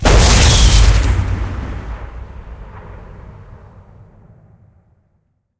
teslaShoot.ogg